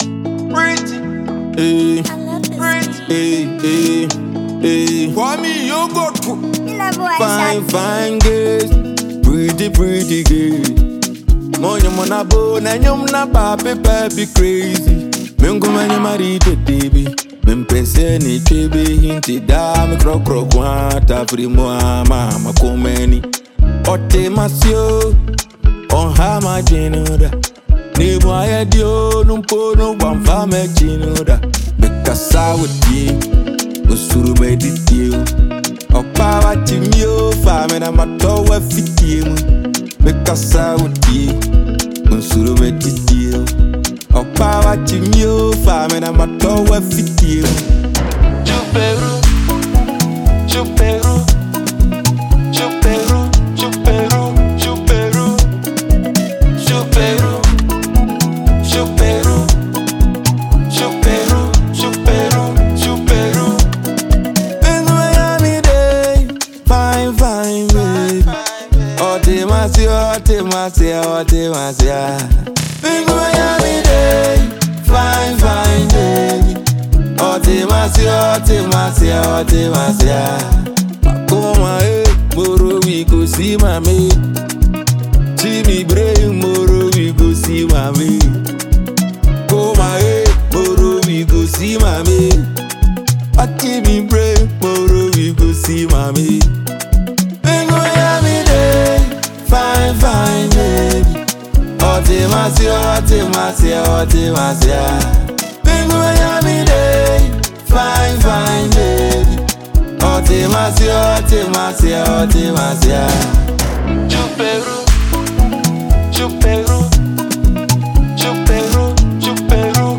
Afro-fusion banger